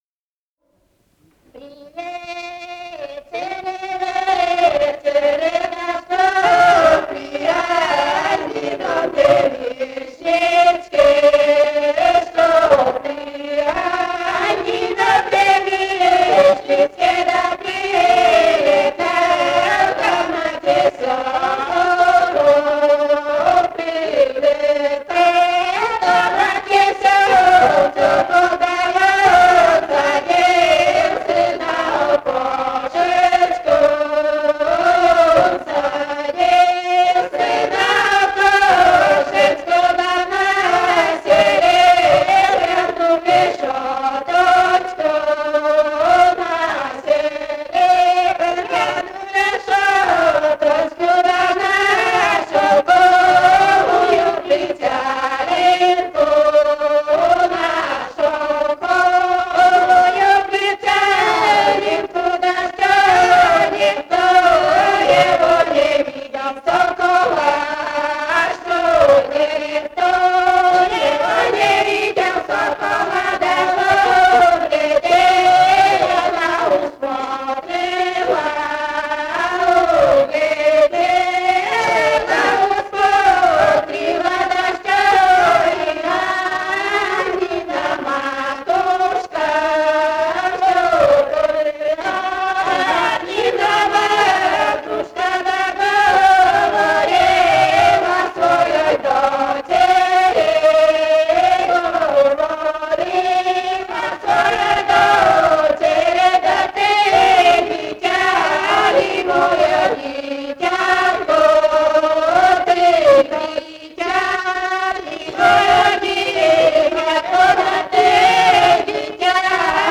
Живые голоса прошлого [[Описание файла::032. «При вечере, вечере» (свадебная на девишнике).